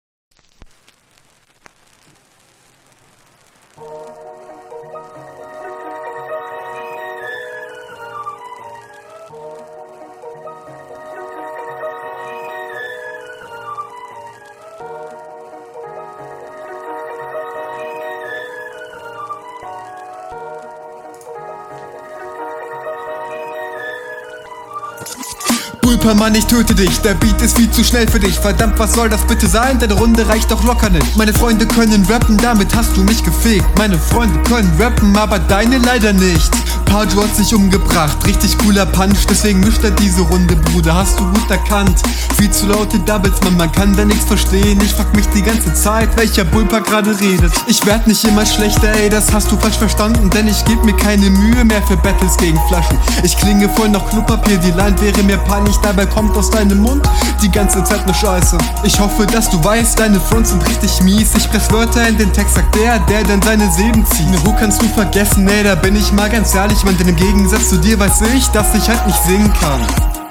Flow stabil, on beat, verständlich.